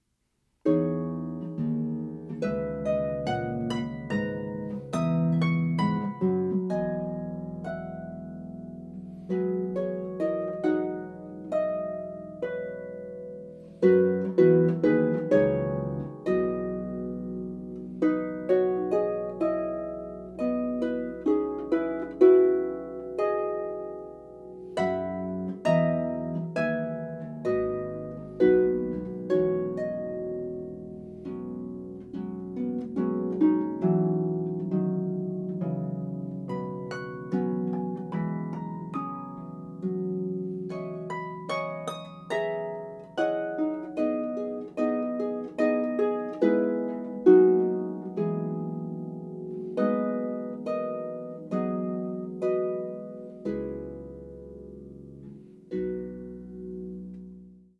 Poem for solo harp